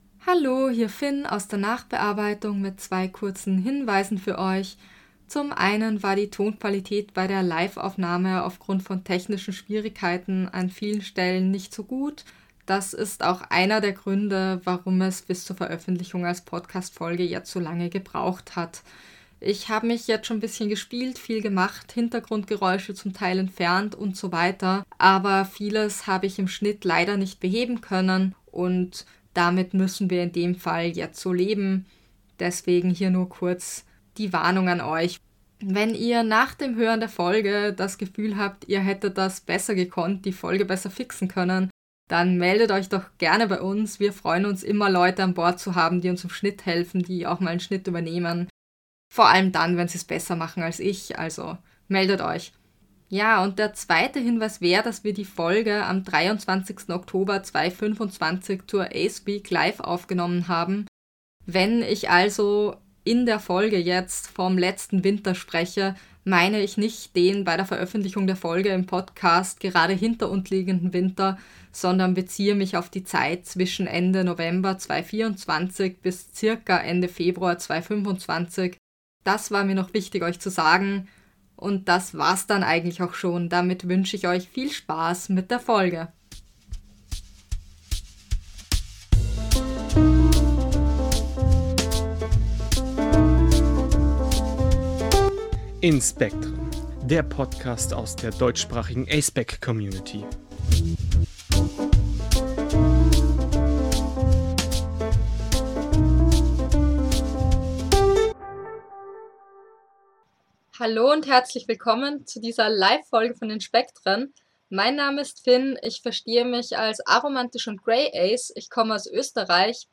88 – Ace*spec in der A*spec-Community (Mitschnitt Livefolge zur Aceweek 2025) – InSpektren